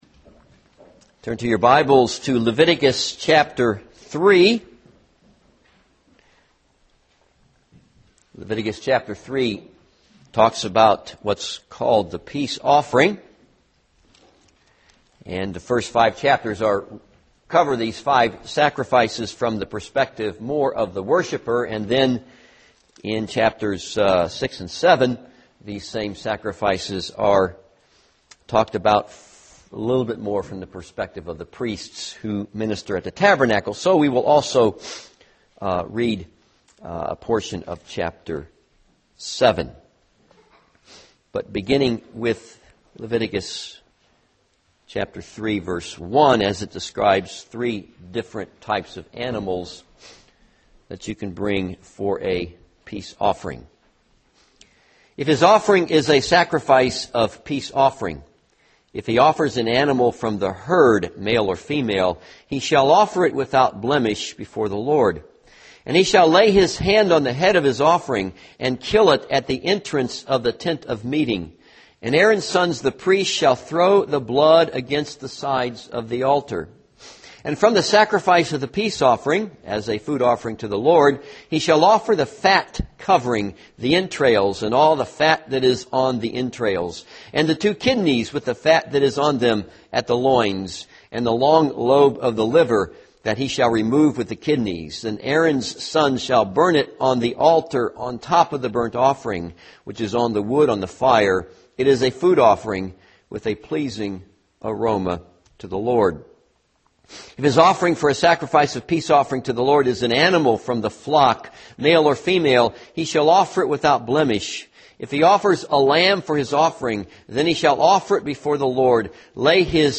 This sermon is based on Leviticus 3 and Leviticus 7:11-38.